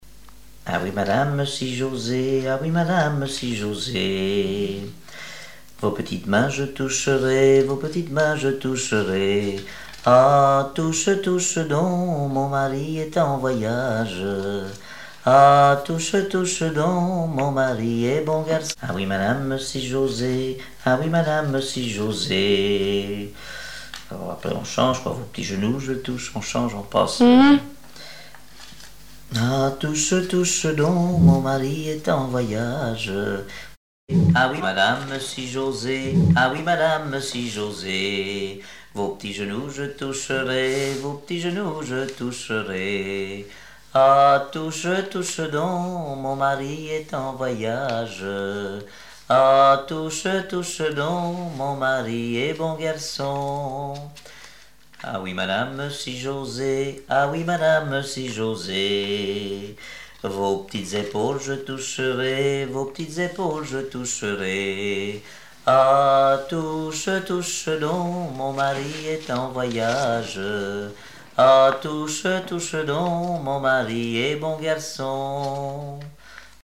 Genre énumérative
Répertoire de chansons traditionnelles et populaires
Pièce musicale inédite